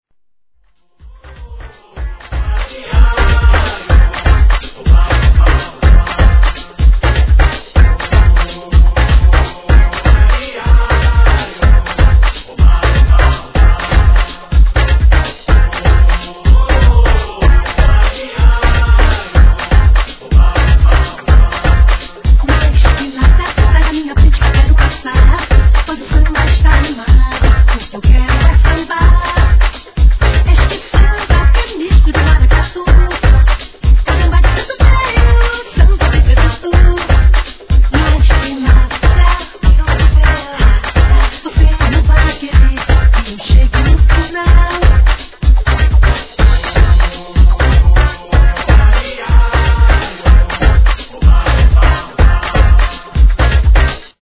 12inch
HIP HOP/R&B